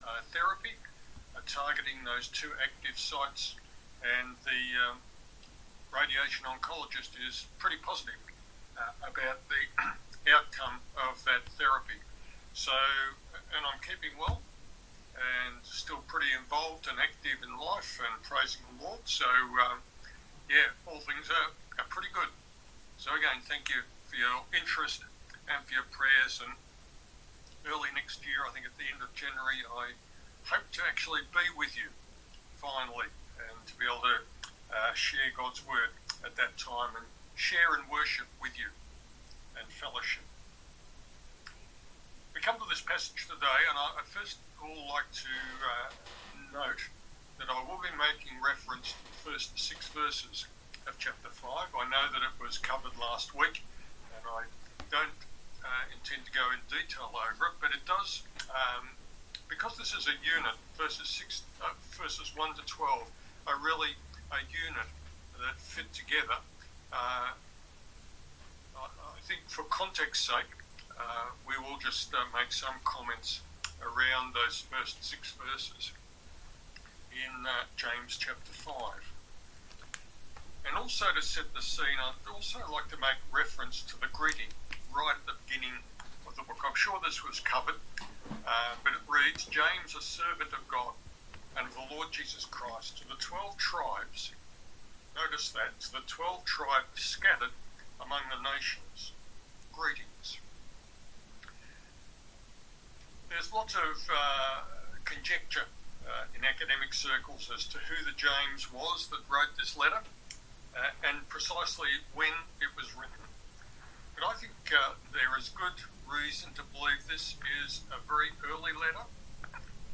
Bible Text: James 5: 7-12 | Preacher